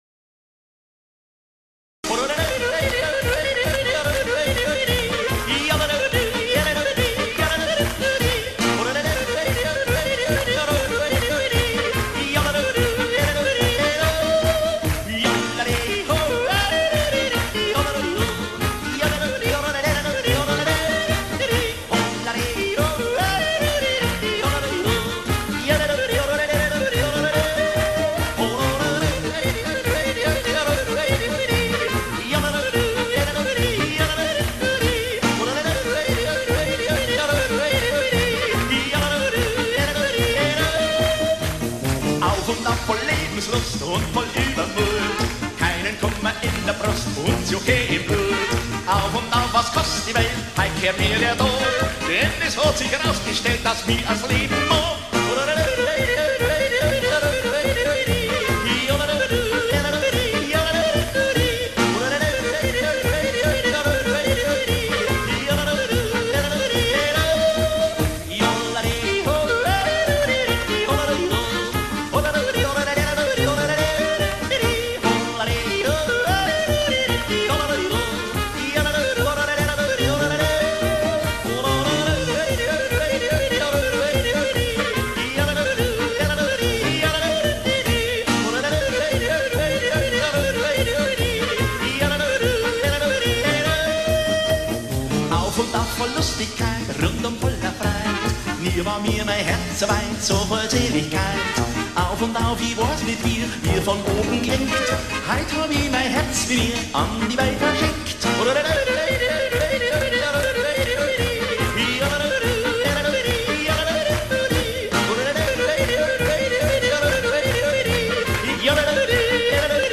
I think yodeling is particularly Swiss.
Here are a couple of yodeling songs I like.